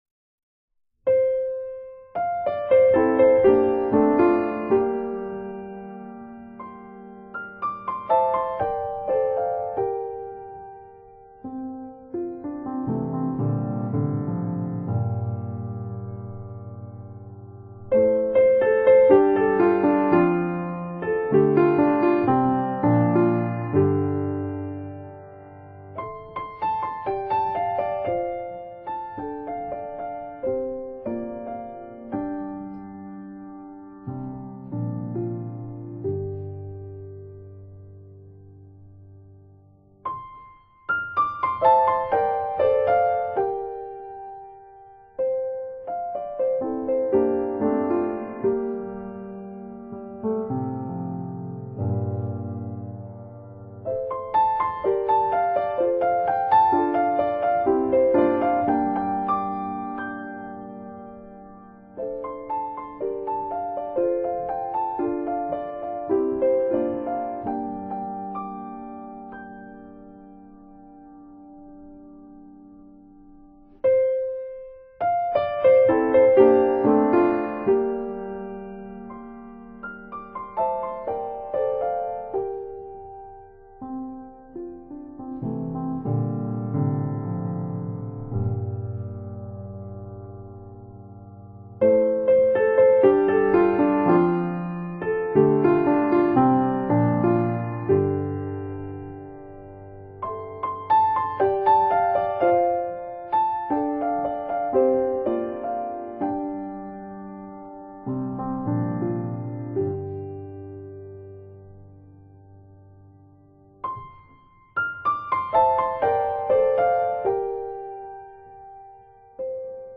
清清澹澹的琴音獻給每一顆豐富的心靈。